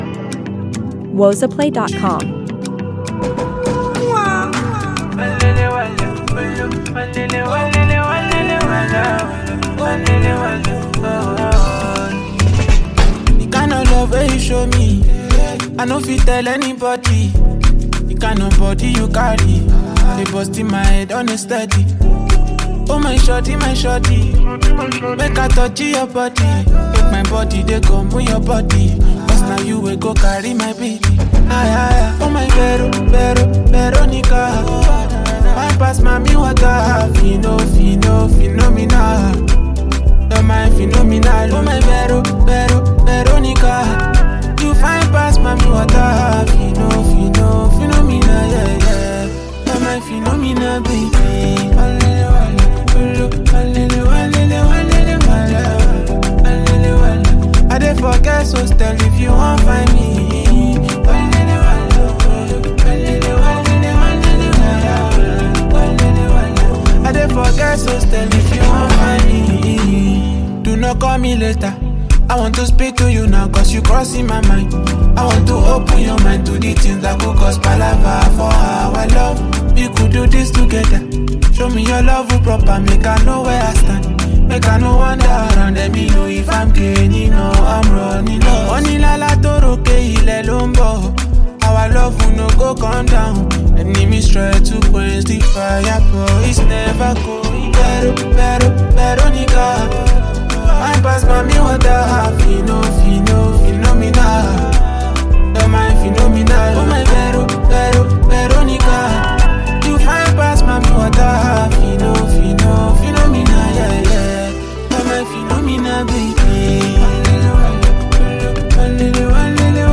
infectious rhythms
creating a vibe that feels fresh and irresistible.